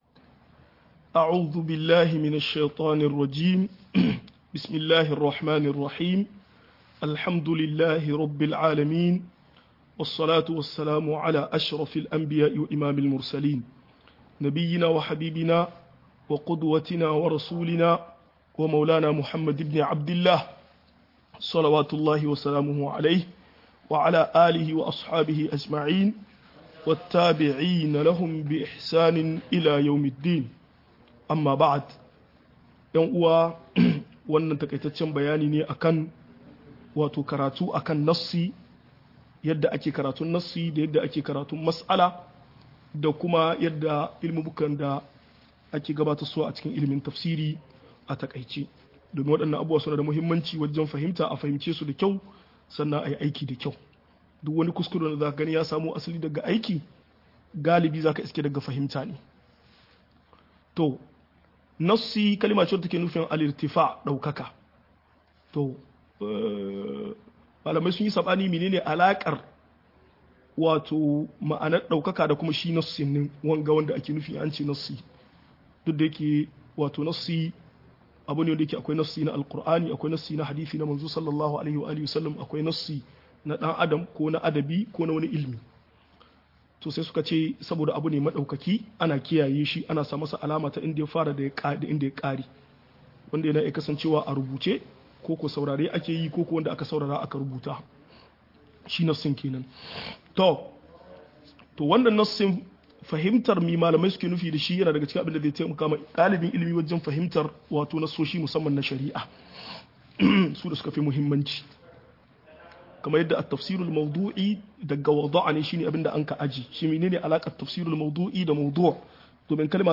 Abinda mai tafsir Yakamata ya sani-1 - MUHADARA